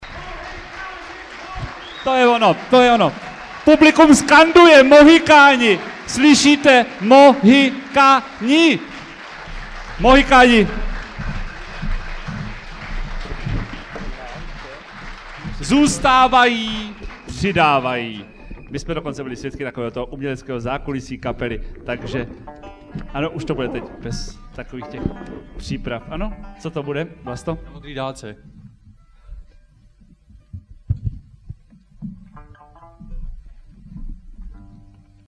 Nahrávka pochází z kotoučáku pana zvukaře.